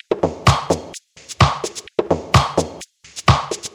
VEH1 Fx Loops 128 BPM
VEH1 FX Loop - 05.wav